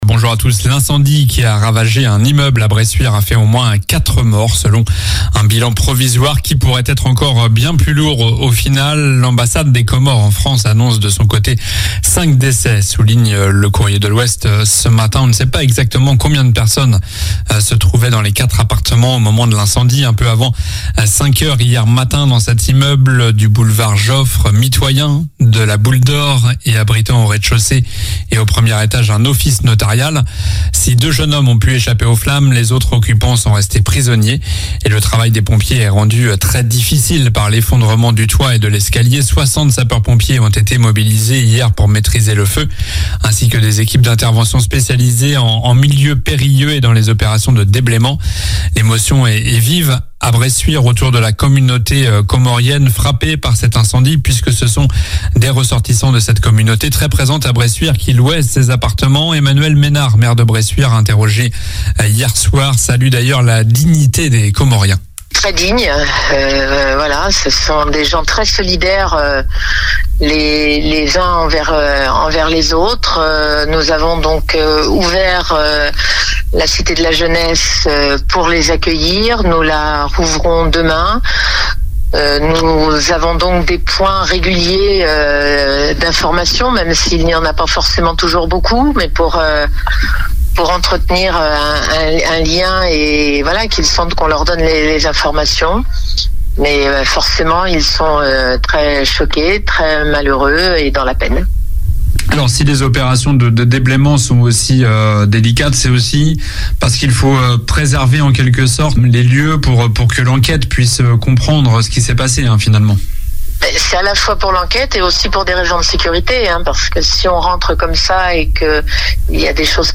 Journal du samedi 9 juillet (matin)